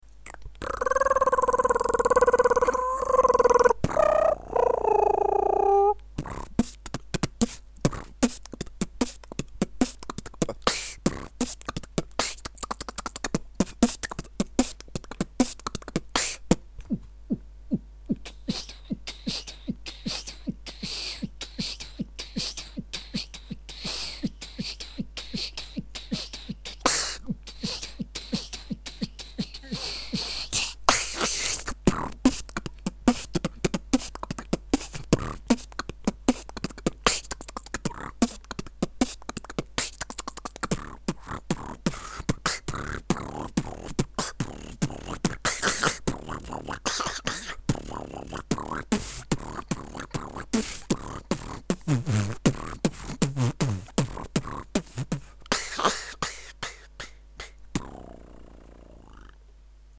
ну и интересное свучание звука brr